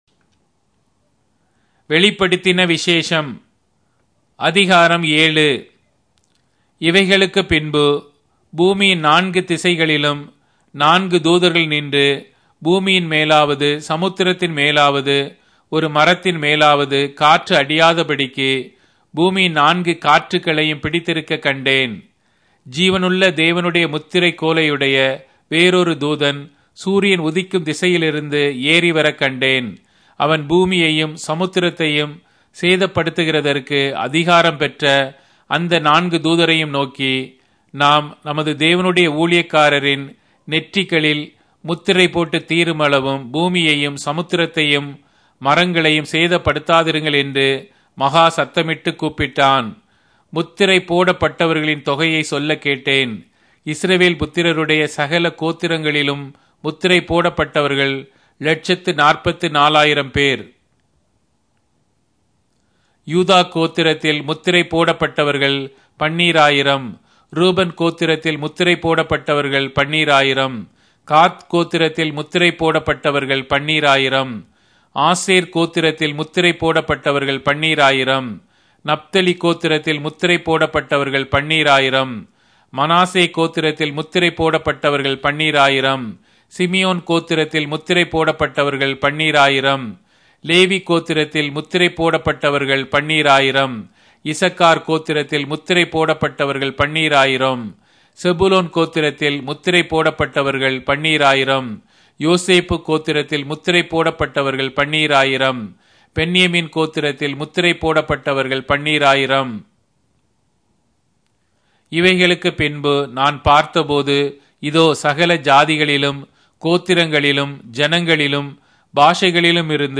Tamil Audio Bible - Revelation 3 in Ervmr bible version